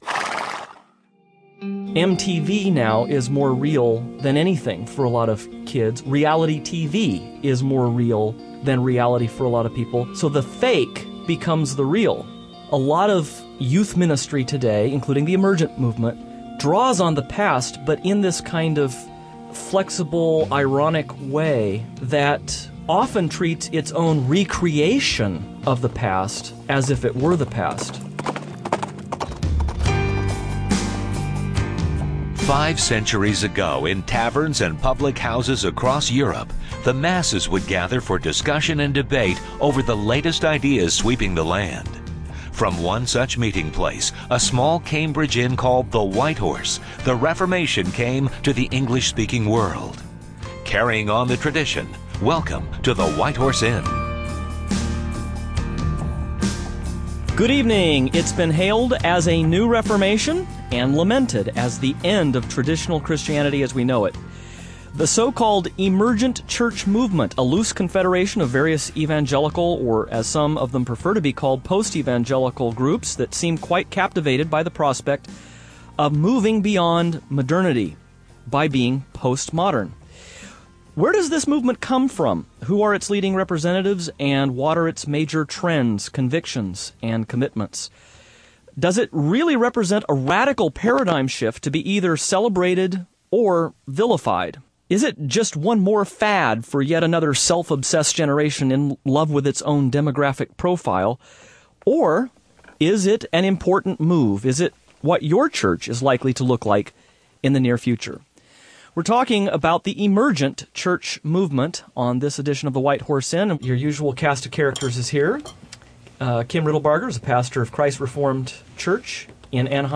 This program will explore these questions, and we'll also be featuring a number of interviews and sound bites from a recent Emergent Church convention.